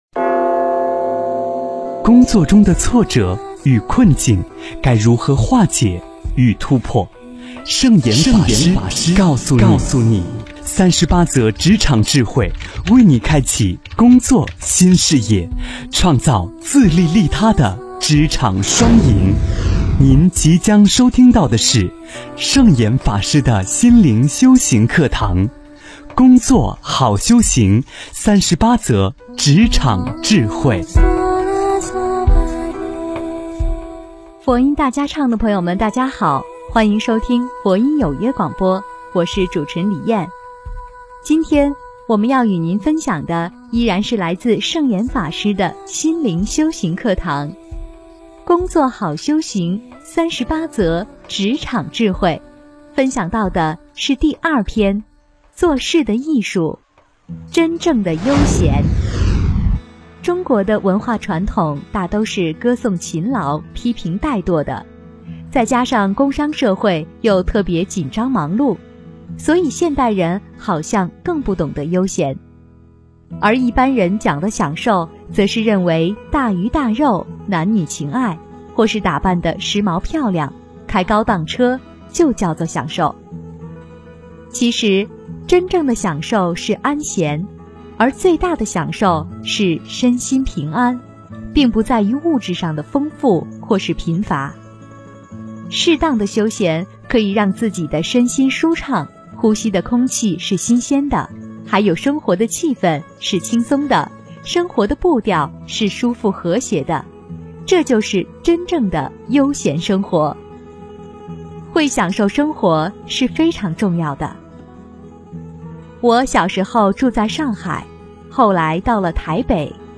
职场21真正的悠闲--佛音大家唱 真言 职场21真正的悠闲--佛音大家唱 点我： 标签: 佛音 真言 佛教音乐 返回列表 上一篇： 职场20调整职场EQ的好方法--佛音大家唱 下一篇： 职场26留住人才的方法--佛音大家唱 相关文章 大宝广博楼阁善住秘密陀罗尼咒--海涛法师 大宝广博楼阁善住秘密陀罗尼咒--海涛法师...